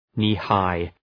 Προφορά
{,ni:’haı}